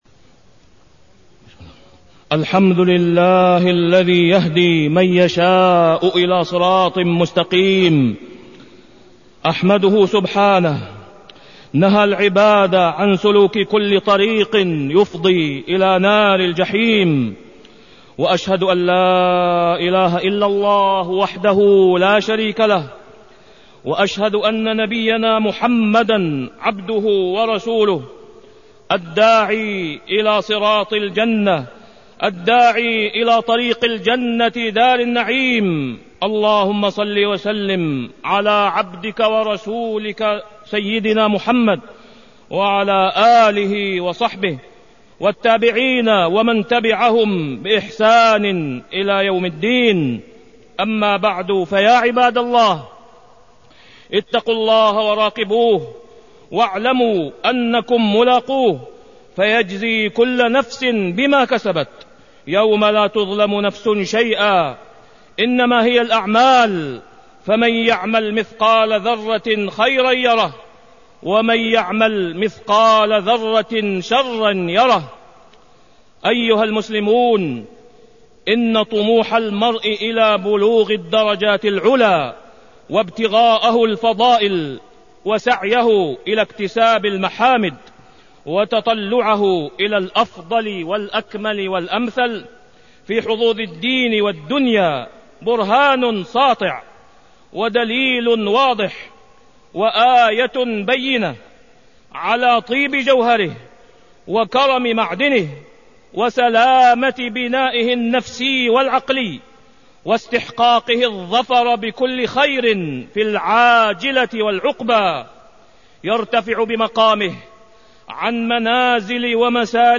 تاريخ النشر ٢٦ جمادى الآخرة ١٤٢٢ هـ المكان: المسجد الحرام الشيخ: فضيلة الشيخ د. أسامة بن عبدالله خياط فضيلة الشيخ د. أسامة بن عبدالله خياط الحسد المحمود والمذموم